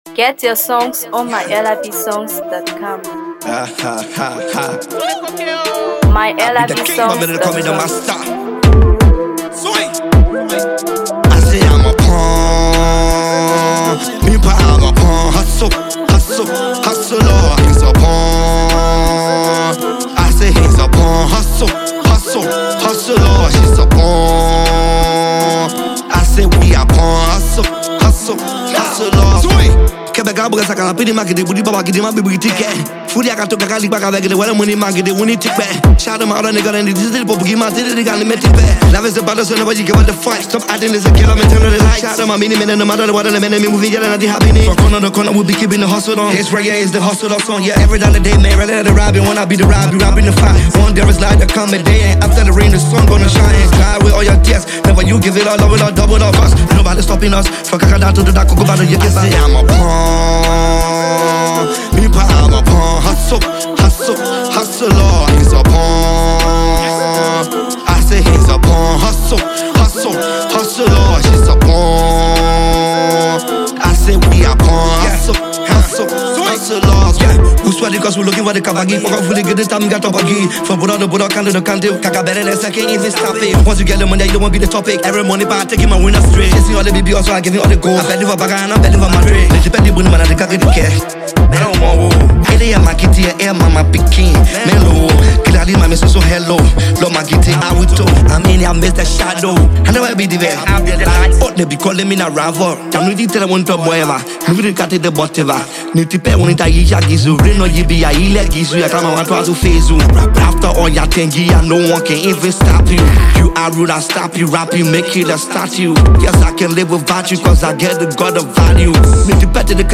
a powerful anthem that speaks directly to the struggles
With his signature blend of Afrobeat and Hipco vibes